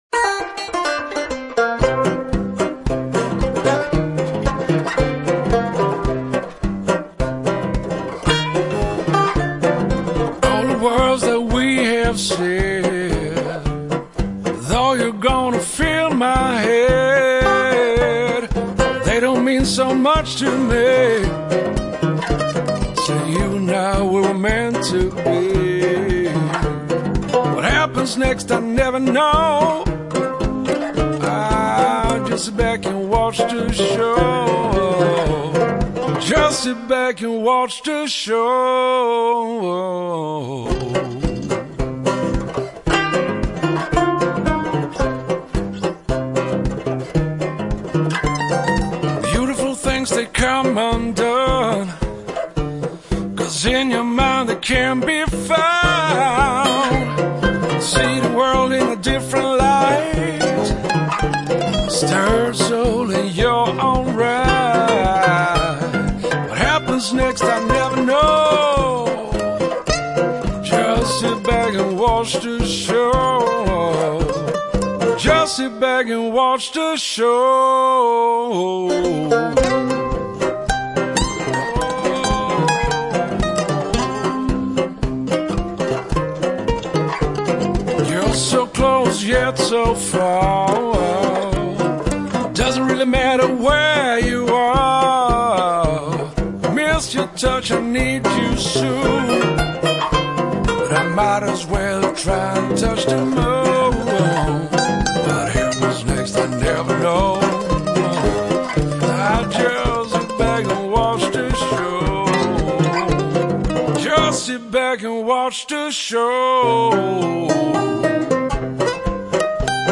Banjo!!!
mandolin